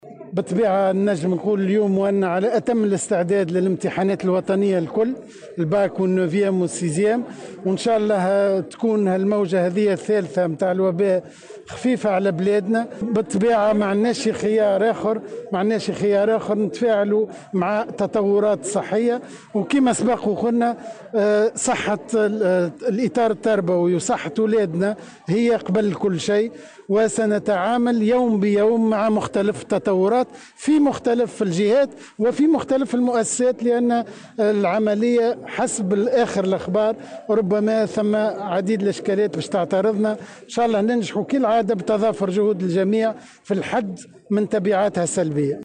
وأضاف في تصريح اليوم لمراسلة "الجوهرة أف أم" على هامش انطلاق الحملة الوطنية لاستخراج بطاقات التعريف الوطنية لتلاميذ السنة الثالثة ثانوي، أن صحة التلاميذ والإطار التربوي من أهم الأولويات، معبّرا عن أمله في إنجاح السنة الدراسية و الحد من تبعات هذه الجائحة السلبية.